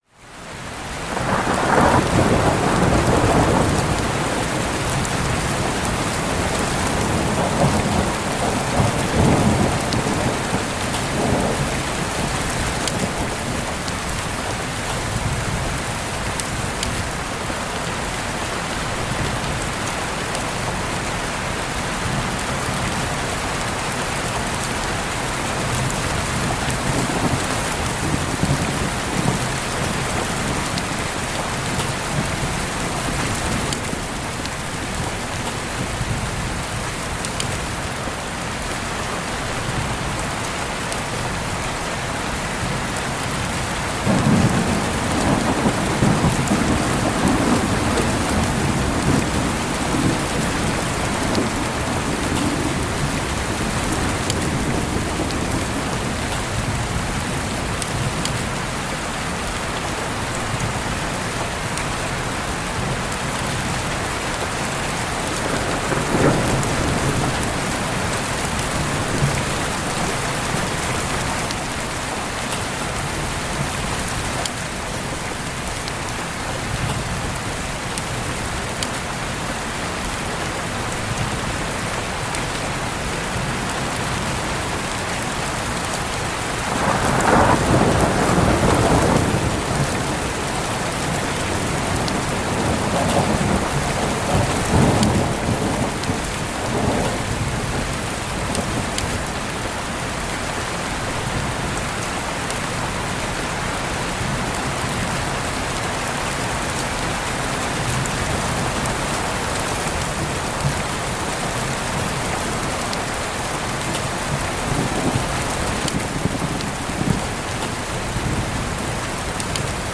Lluvia Fuerte y Trueno para Aliviar Insomnio y Calmar la Mente Atenta